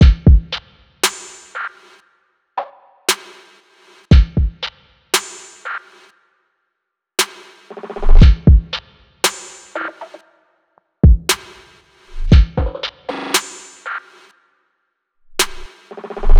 Trip Drum Loop (117 bpm)